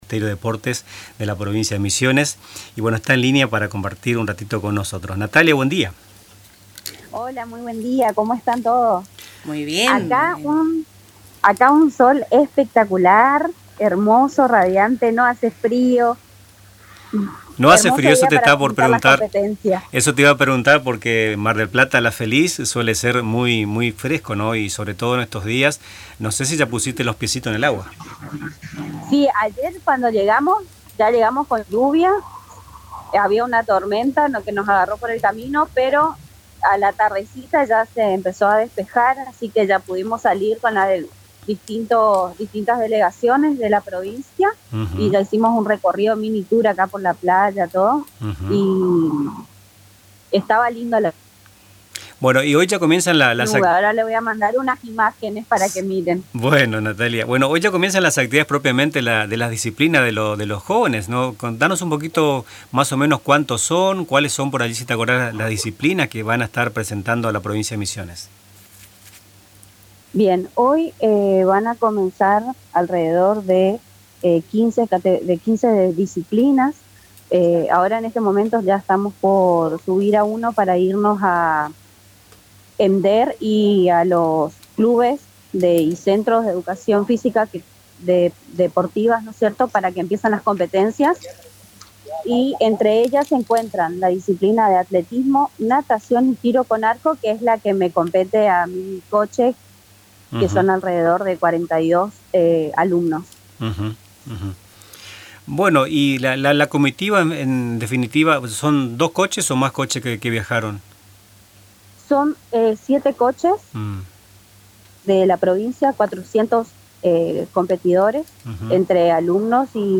En diálogo con Radio Tupa Mbae